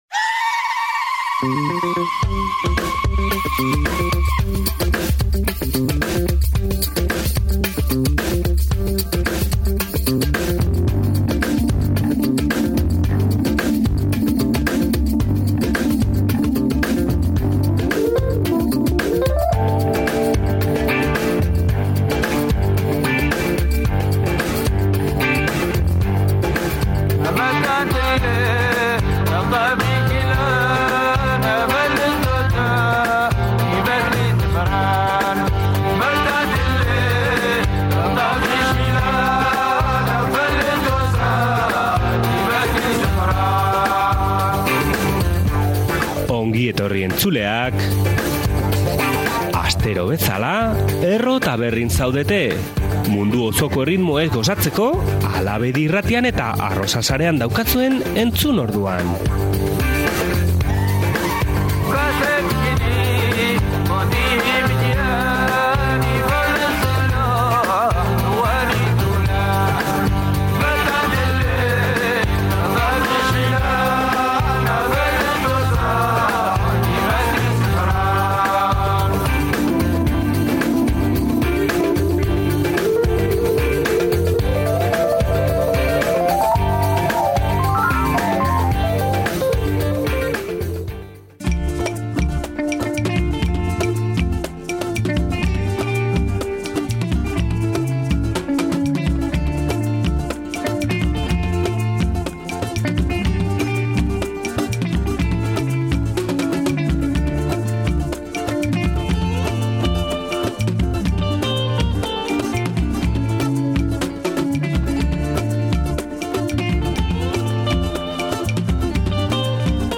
Garifuna kulturako musikarekin hasiko dugu era alaiean aste honetako Erro Ta Berri. Ondoren, Haiti, Mexiko eta AEBetan bizi diren artista latinoak izango ditugu entzungai. Amaieraran Euskal Herrian izango diren 3 emakumeen kontzertuak jakinaraziko dizkizuet.